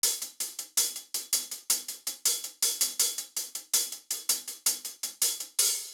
Try Often HiHat Loop.wav